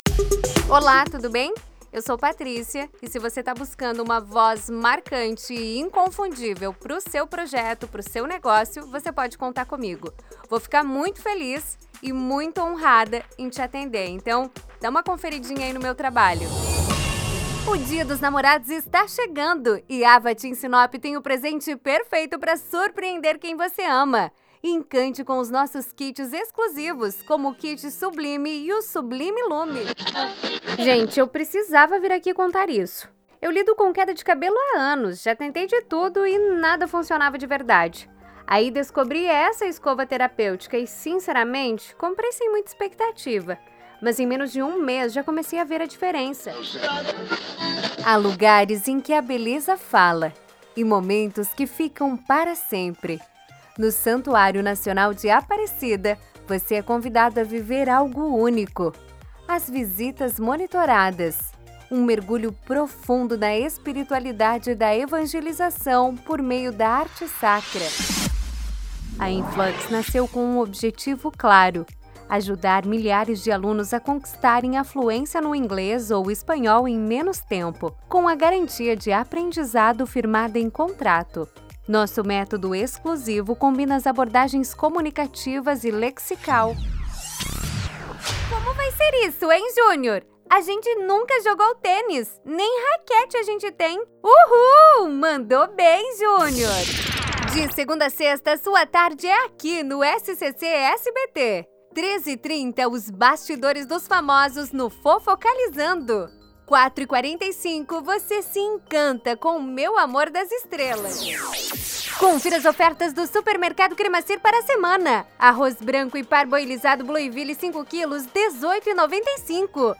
Spot Comercial
Vinhetas
Padrão
Animada
Ótima locutora!